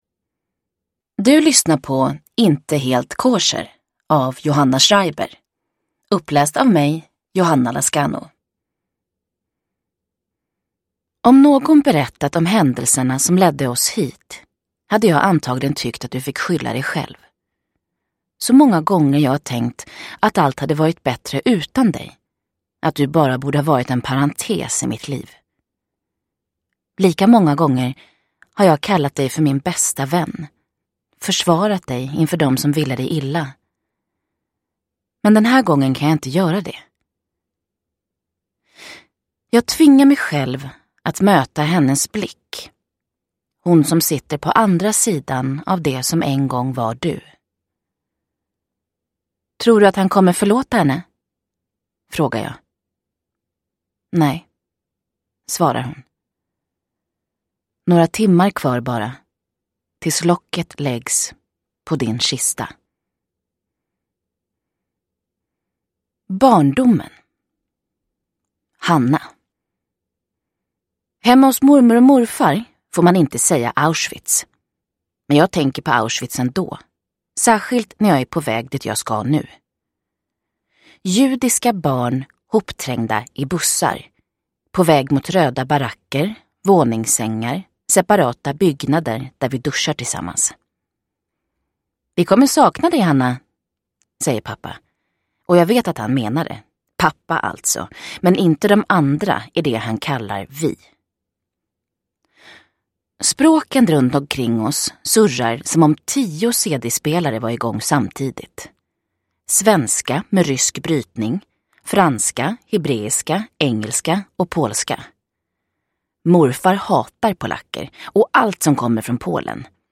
Inte helt kosher – Ljudbok